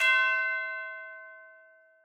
FX_TubularBells.wav